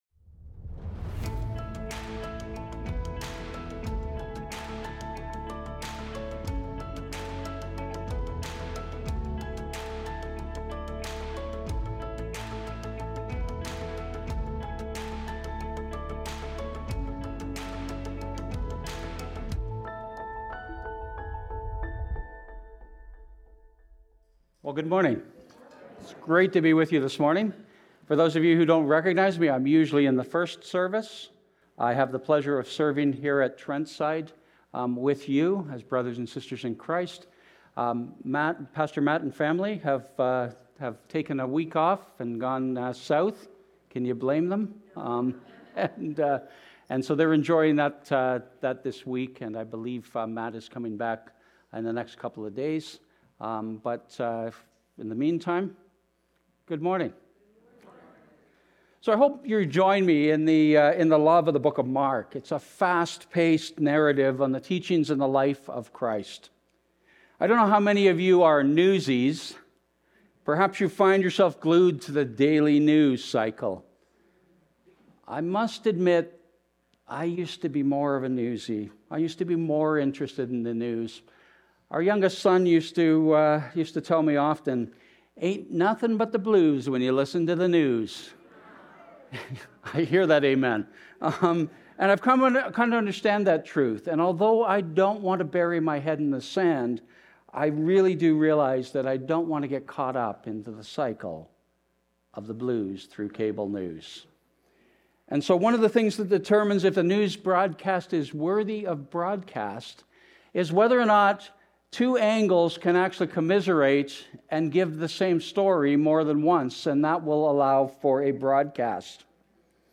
Recorded Sunday, March 22, 2026, at Trentside Fenelon Falls.